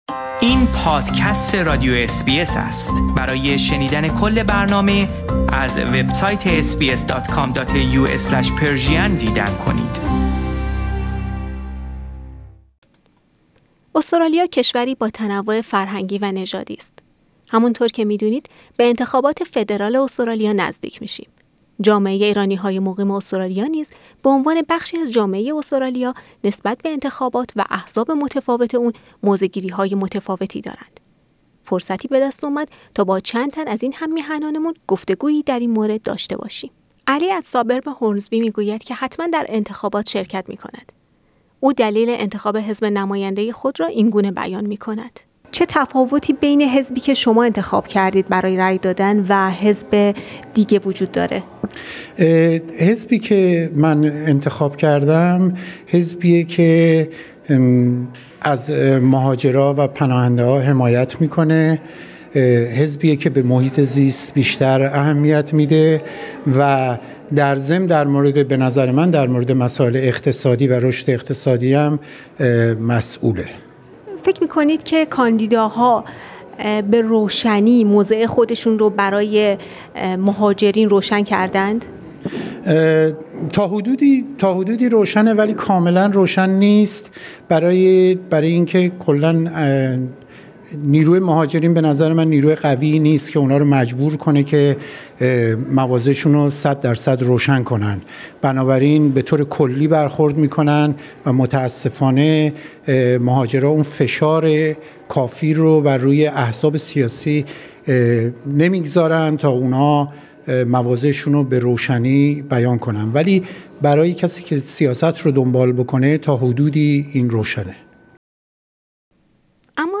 فرصتی دست داد تا با چند تن از ایرانیان سامن استرالیا گفتگویی در این مورد داشته باشیم و دلایل آن ها را برای شرکت یا عدم شرکت در انتخابات و چگونگی انتخاب نماینده خود بشنویم.